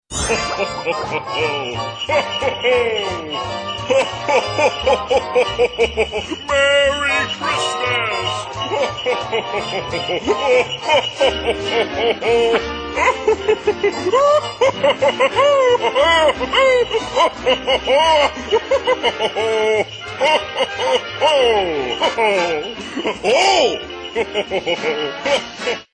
Категория: Смешные реалтоны